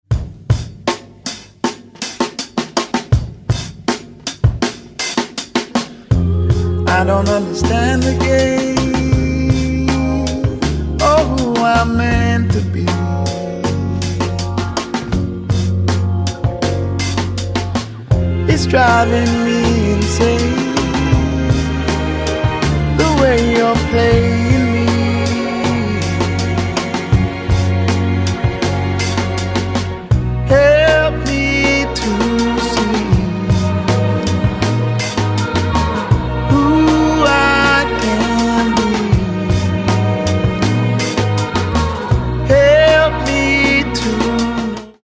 Genre : R&B/Soul